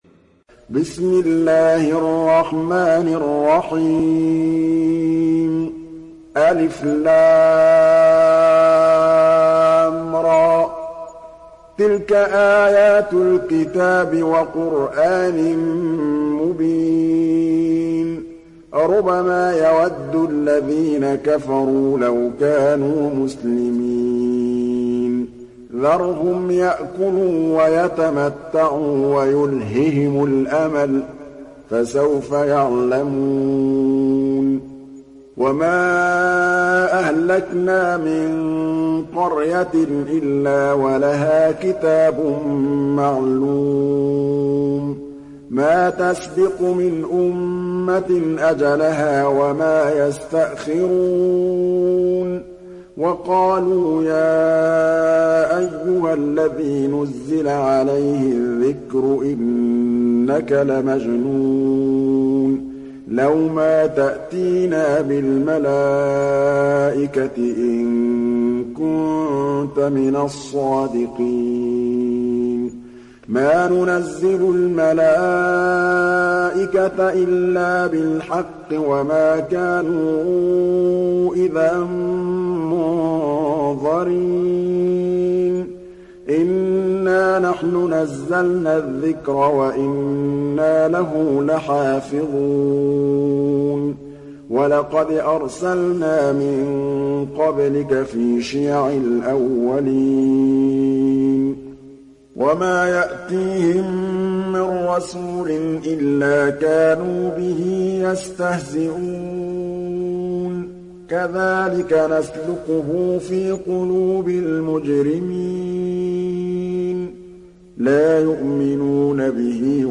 Sourate Al Hijr Télécharger mp3 Muhammad Mahmood Al Tablawi Riwayat Hafs an Assim, Téléchargez le Coran et écoutez les liens directs complets mp3